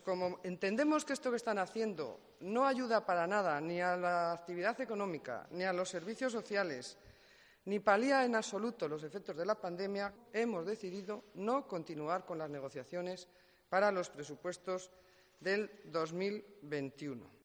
EL ALCALDE DE VALLADOLID RESPONDE A LA PRESIDENTA DEL GRUPO MUNICIPAL DEL PP